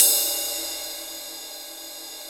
Index of /90_sSampleCDs/Roland L-CD701/CYM_Rides 1/CYM_Ride Modules